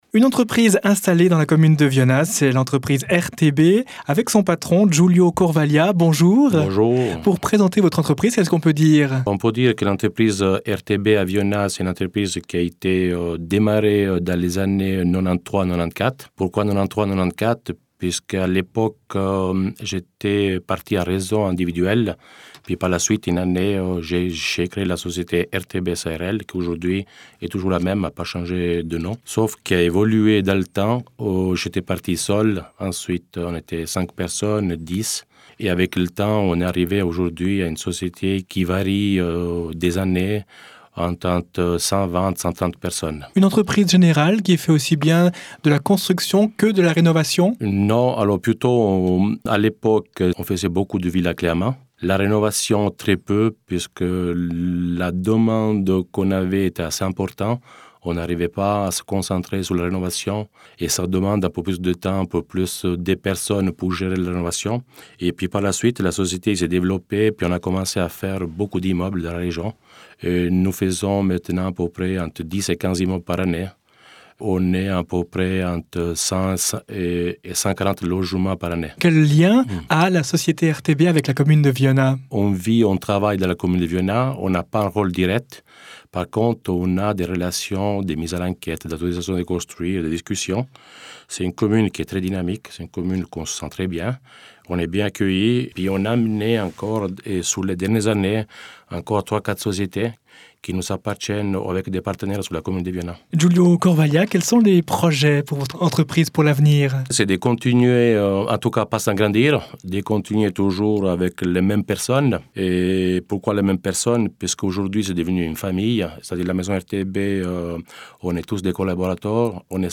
Rubriques enregistrées avec RhôneFM, diffusées les vendredis à 8h50